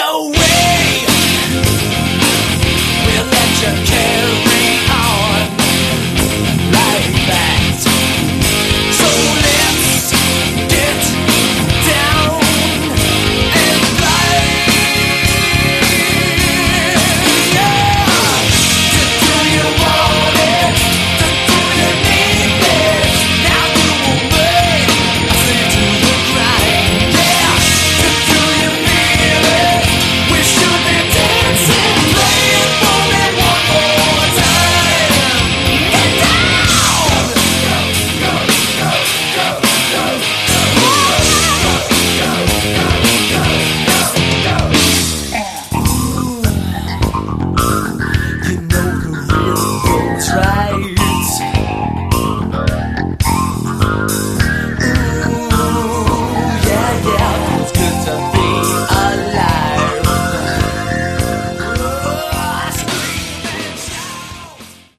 Category: Hard Rock
lead vocals, lead guitar
bass guitar, backup vocals
drums, keyboard programming, backing vocals